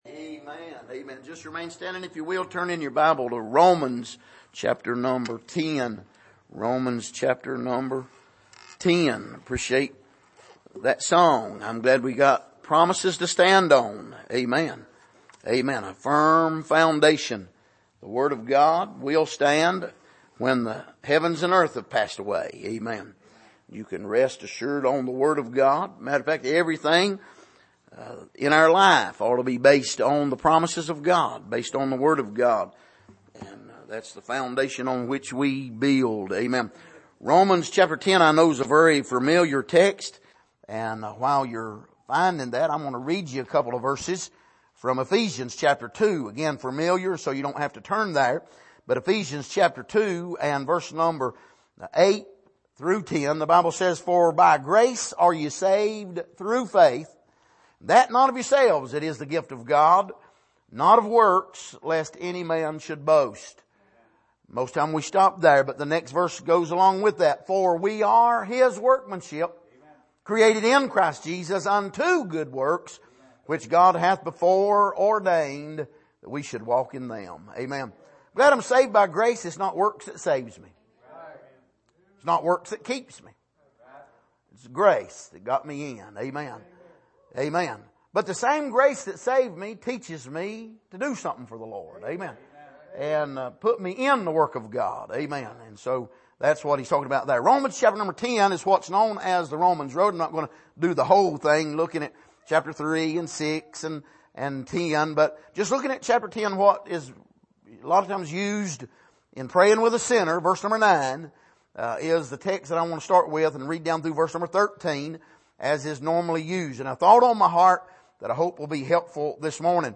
Passage: Romans 10:9-13 Service: Sunday Morning